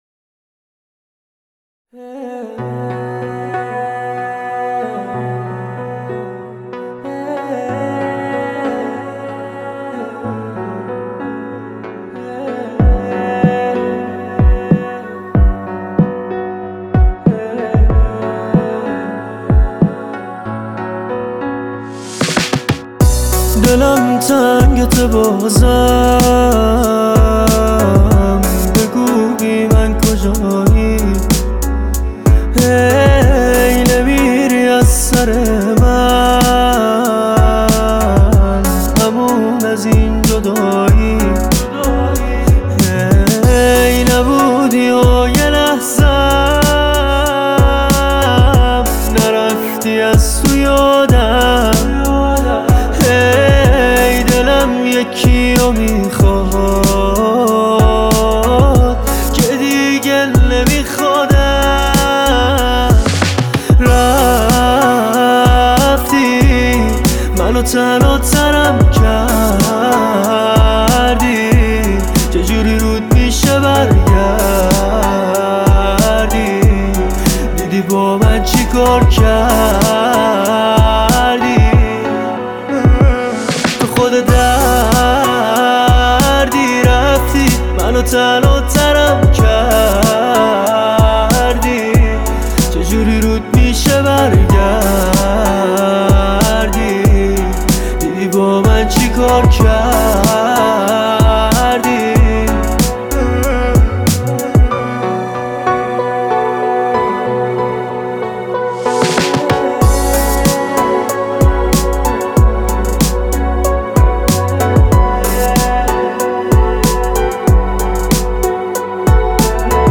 ترانه بسیار زیبا و احساسی